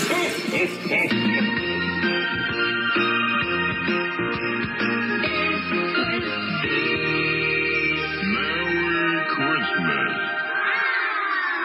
Indicatiu de Nadal de l'emissora